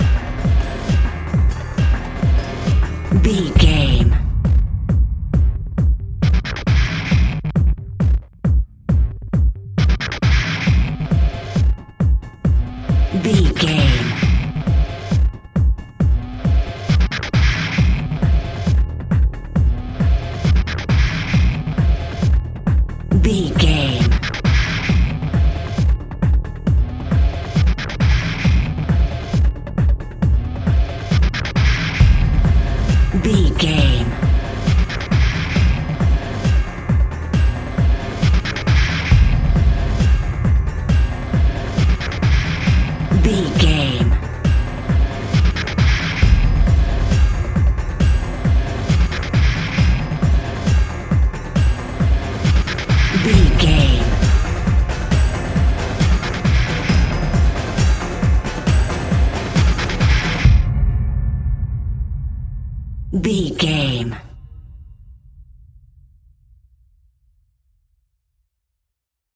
Aeolian/Minor
Fast
tension
ominous
dark
eerie
driving
synthesiser
drums
drum machine